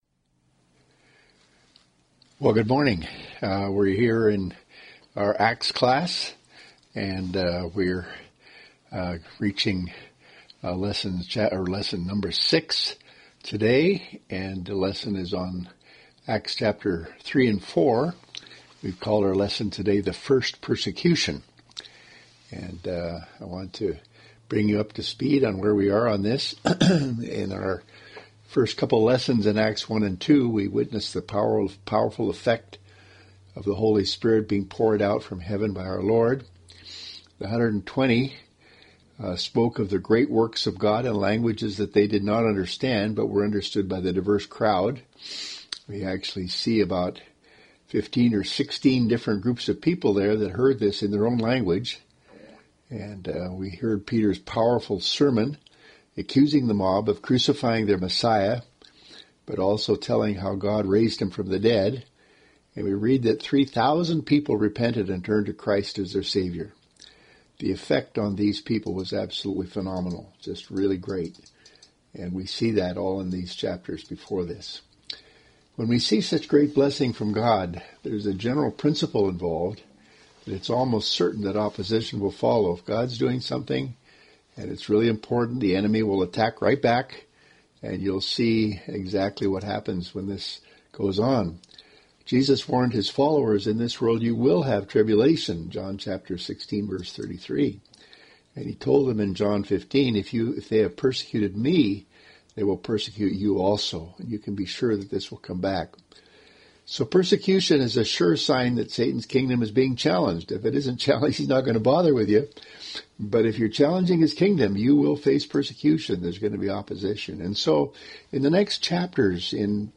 Sunday School – Acts – Pt. 6 – Covenant Presbyterian Church